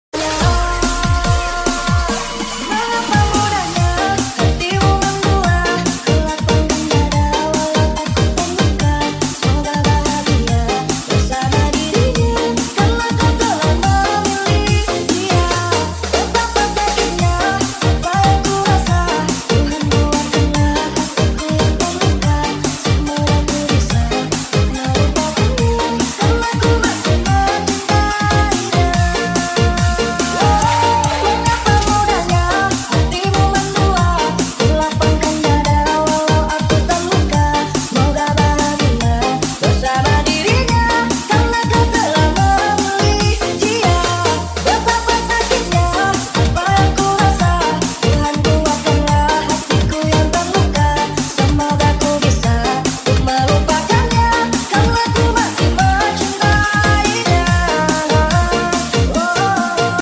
BMW M4 Drifitng Beamng Drive Sound Effects Free Download